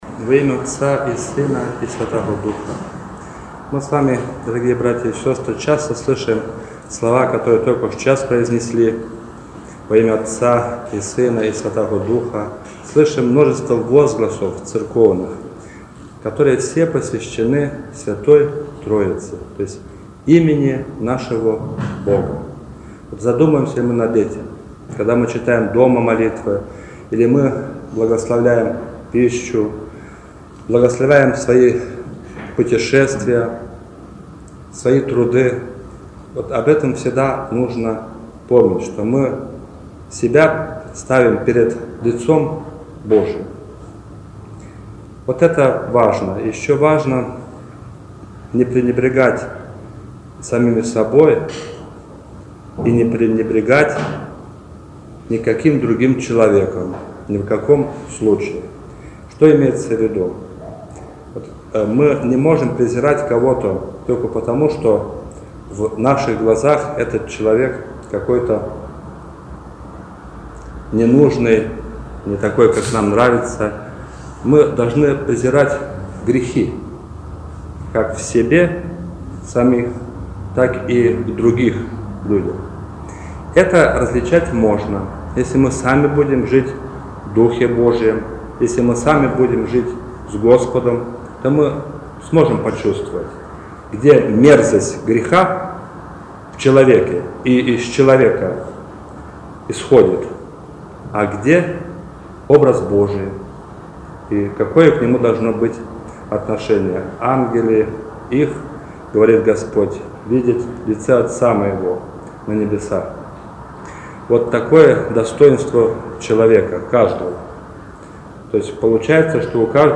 Проповедь
после Литургии в день Святого Духа